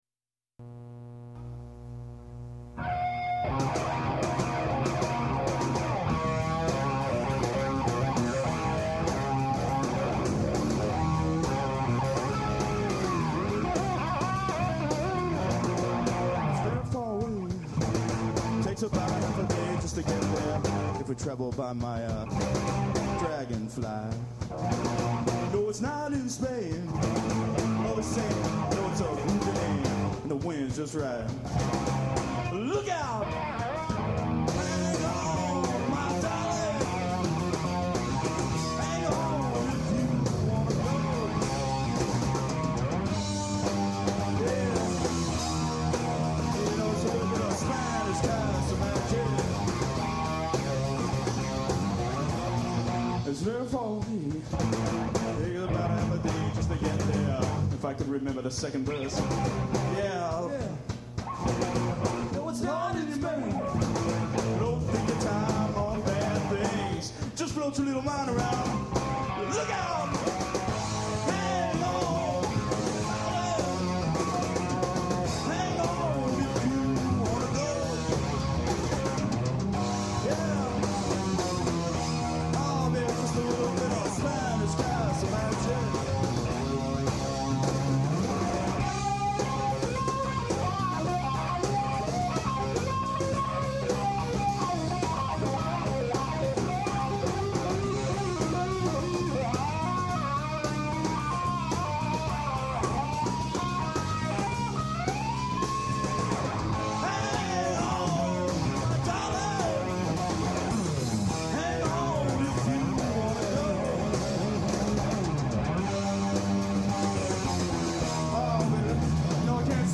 Sorry for the forgotten 2nd verse.